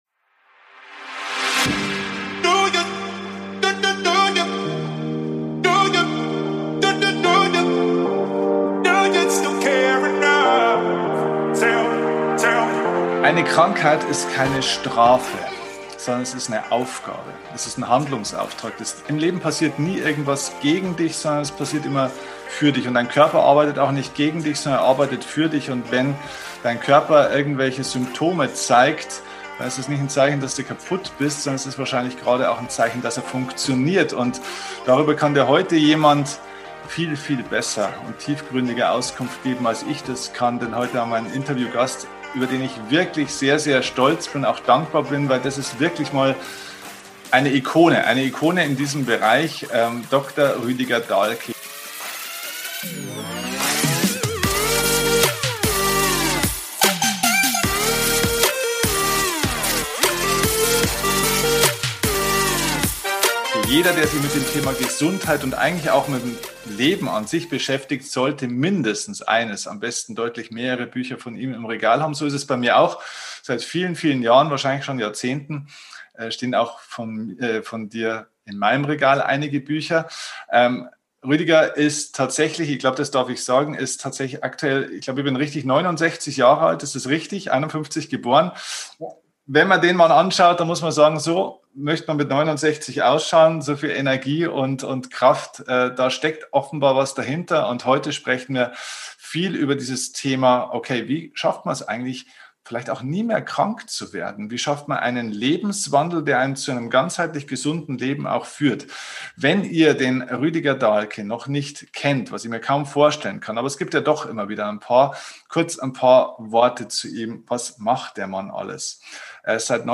Beide Teile unseres gemeinsamen Gesprächs bieten Dir sehr viel Inhalte und Impulse, passend zu dieser Zeit.